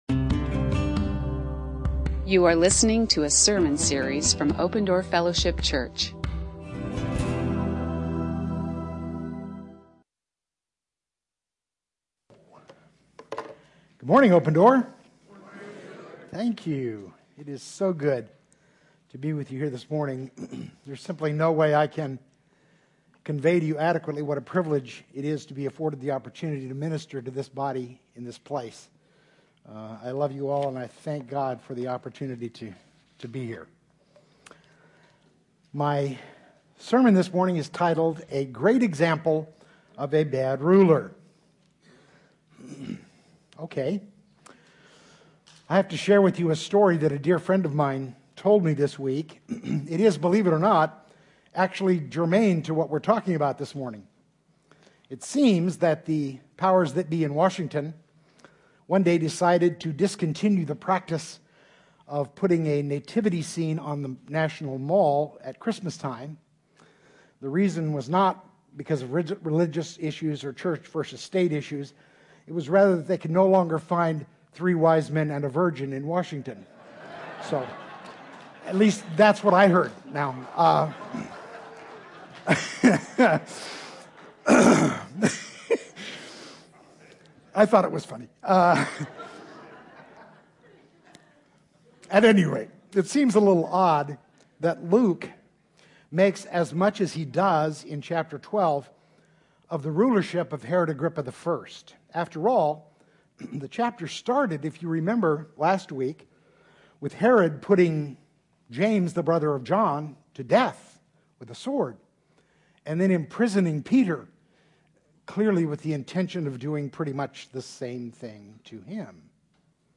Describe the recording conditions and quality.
at Open Door Fellowship Church, Phoenix